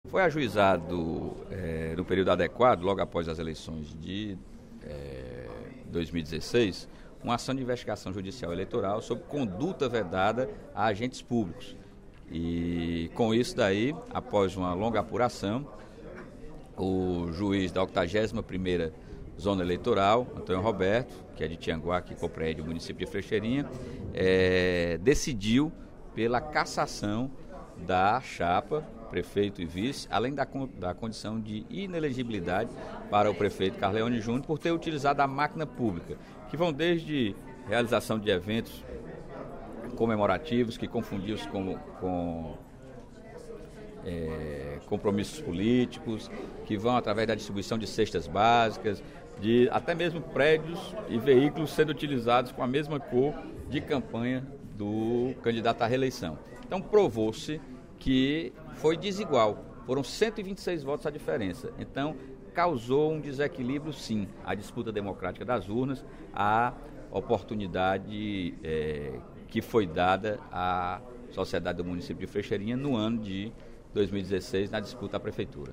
O deputado Sérgio Aguiar (PDT) comentou, durante o primeiro expediente  da sessão plenária desta quinta-feira (06/07), a cassação do prefeito do município de Frecheirinha, Carleone Júnior de Araújo.